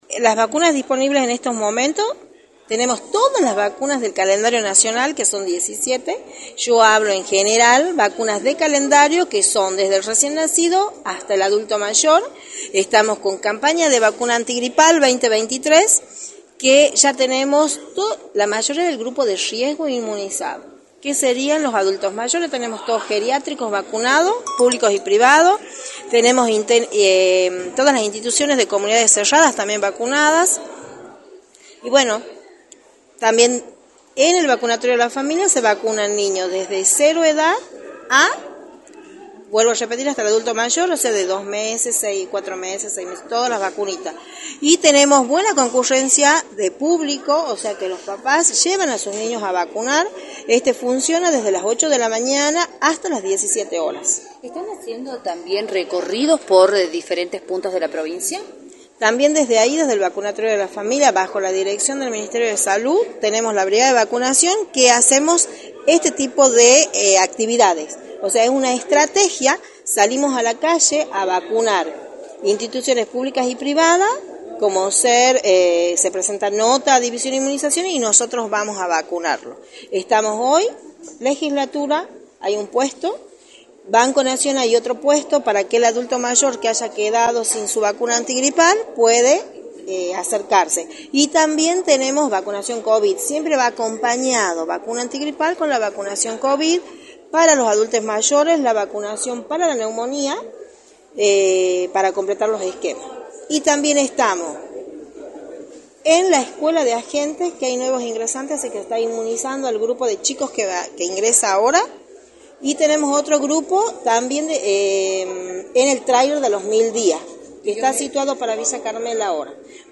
en entrevista para “La Mañana del Plata”